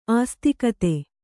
♪ āstikate